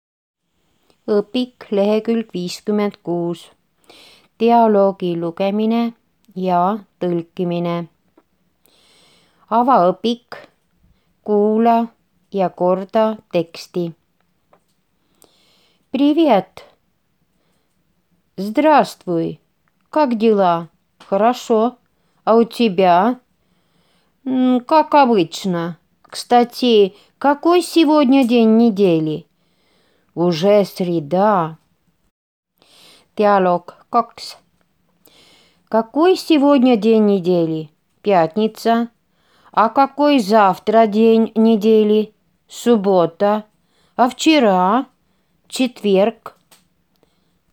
Vene k dialoog NÄDALAPÄEVAD õpik lk 56.m4a